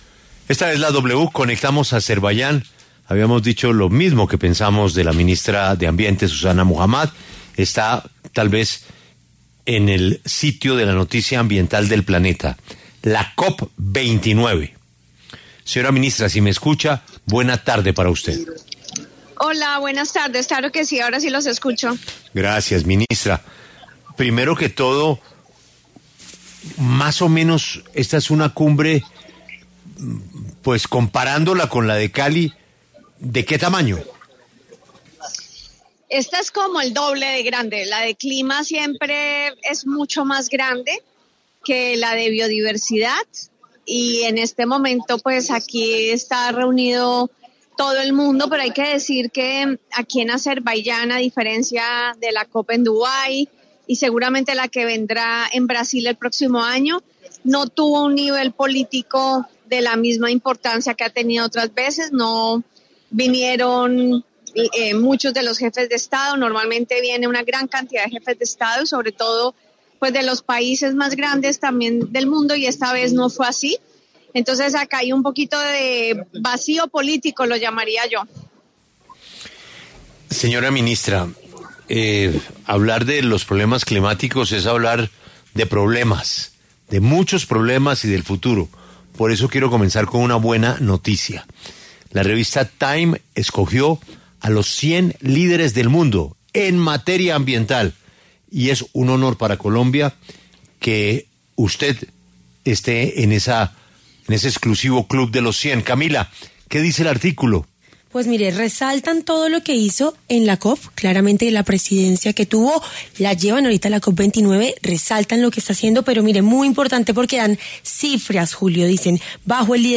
La ministra Susana Muhamad habló en La W desde Azerbaiyán, en donde se desarrolla la COP29 sobre el cambio climático.